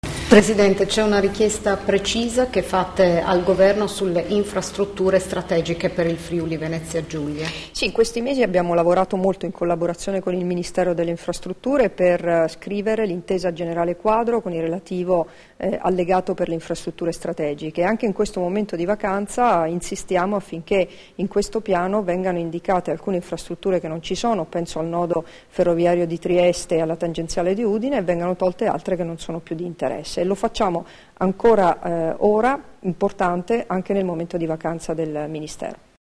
Dichiarazioni di Debora Serracchiani (Formato MP3) sulle infrastrutture strategiche, rilasciate a margine della riunione della Giunta regionale del FVG, a Trieste il 21 febbraio 2014 [581KB]